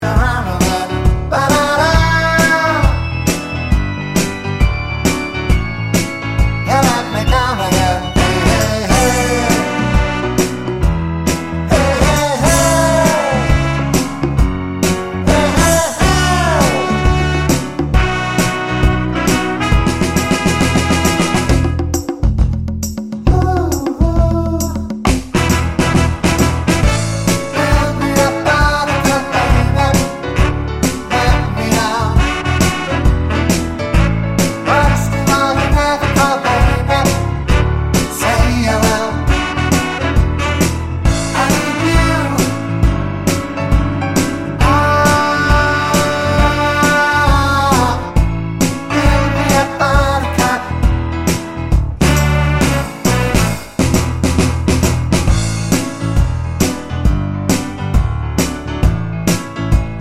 Three Semitones Down Pop (1960s) 3:03 Buy £1.50